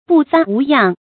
布帆无恙 bù fán wú yàng
布帆无恙发音
成语注音 ㄅㄨˋ ㄈㄢ ㄨˊ ㄧㄤˋ